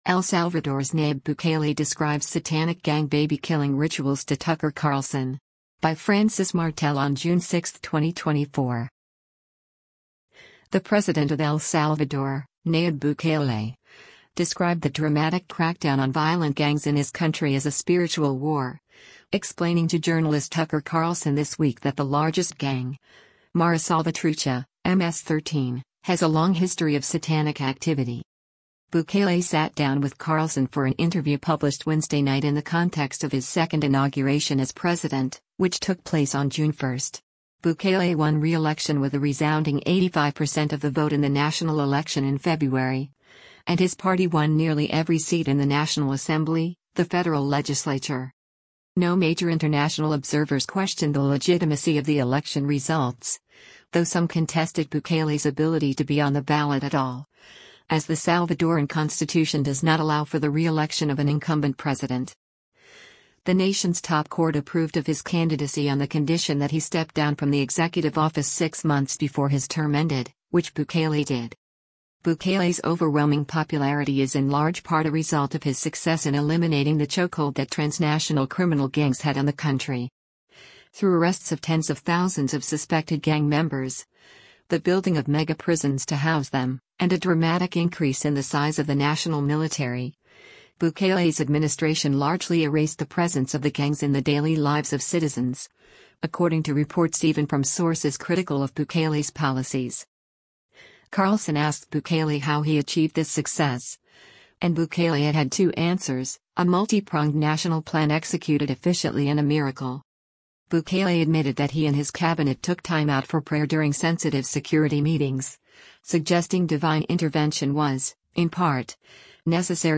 Bukele sat down with Carlson for an interview published Wednesday night in the context of his second inauguration as president, which took place on June 1.